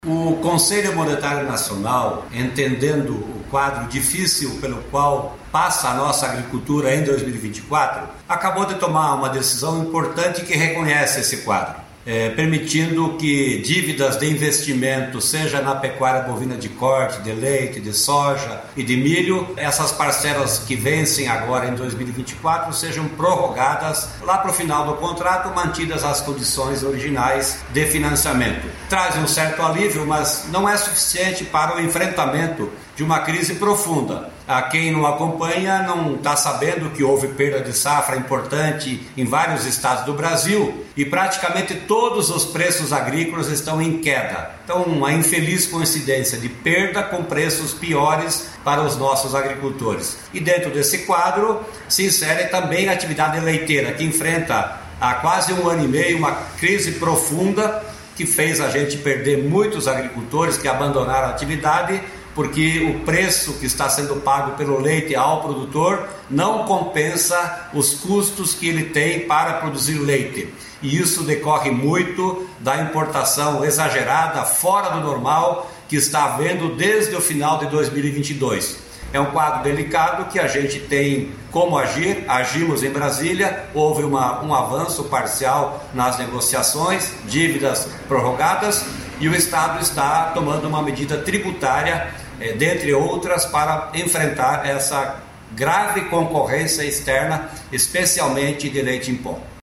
Sonora do secretário da Agricultura, Norberto Ortigara, sobre o Conselho Monetário Nacional autorizar produtores a renegociarem dívidas de crédito rural